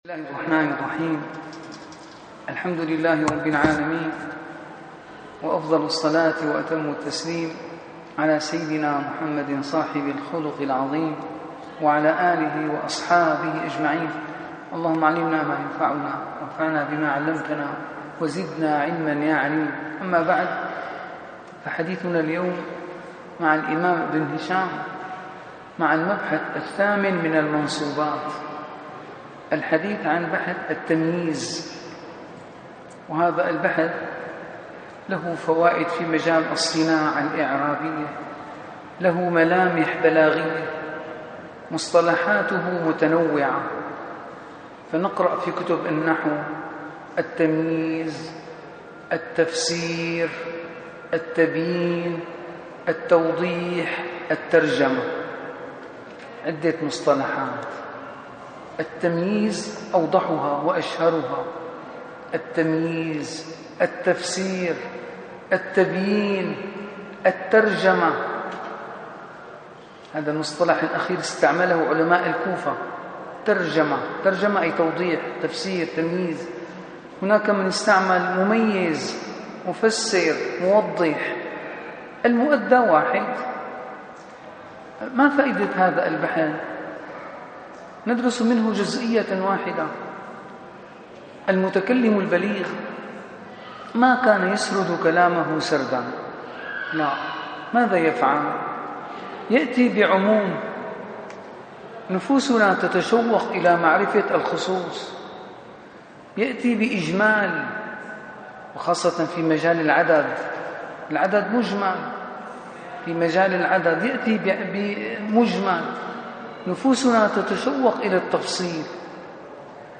- الدروس العلمية - شرح كتاب شذور الذهب - 84- شرح كتاب شذور الذهب: التمييز